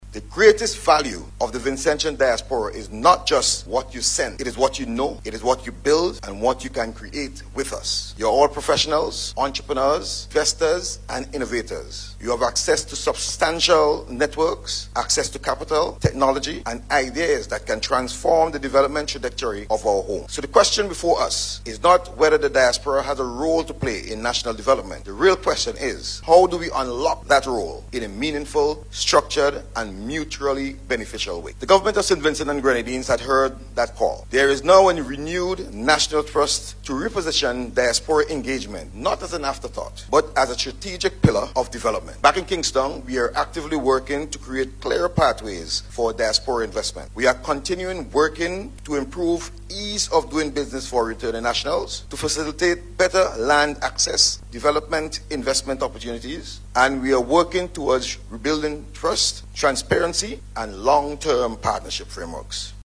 The commendation came from Director of the Regional Integration and Diaspora Unit and Ambassador to Caricom, H.E. Allan Alexander, during his remarks at an investment conference hosted by Invest SVG in London on Sunday.